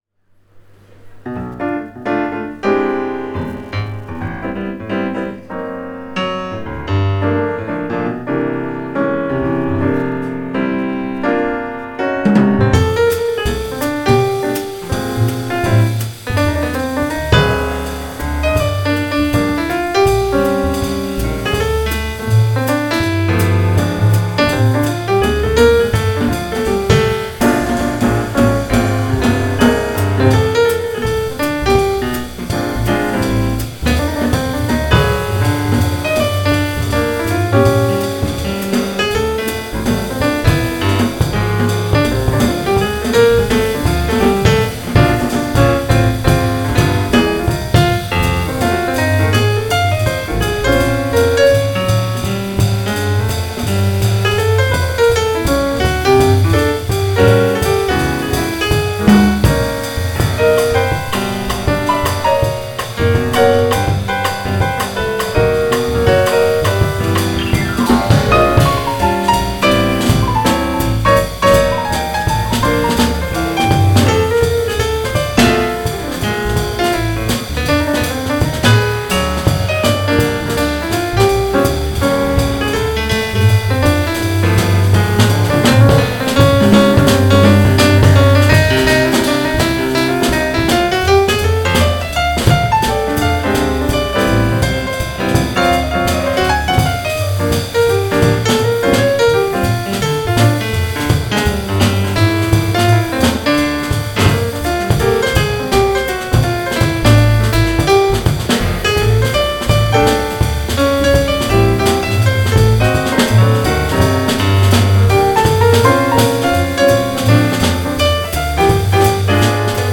Live in Bangkok
jazz Trio
Keyboards
Bass
Drums